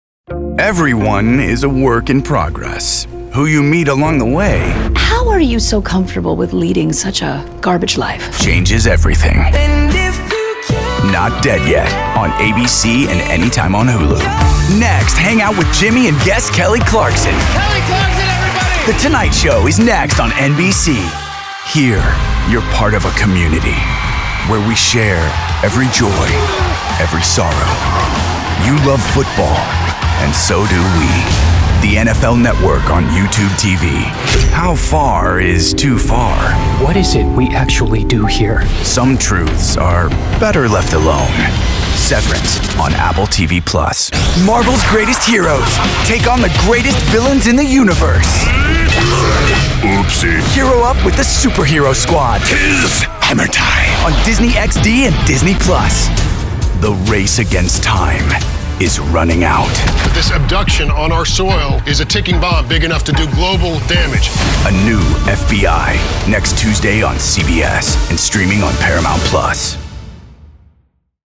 Promo
• Promo/Imaging